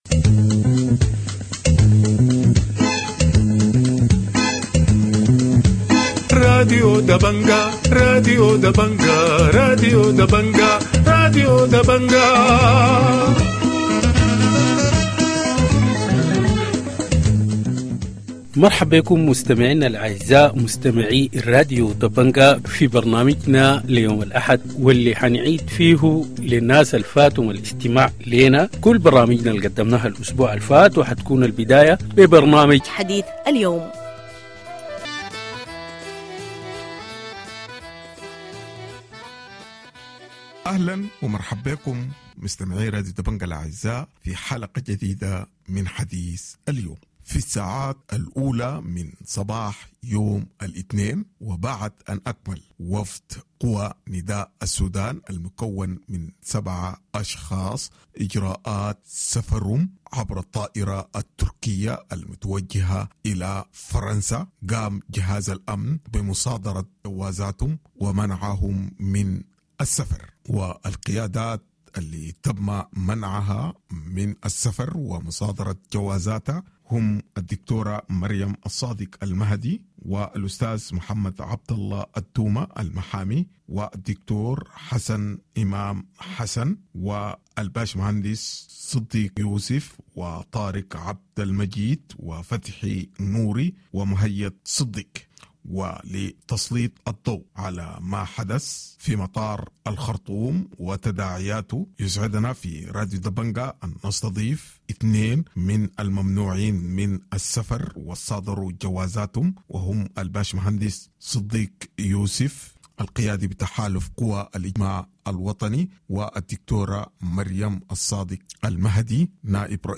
Evening news 14 June - Dabanga Radio TV Online
Weekend news review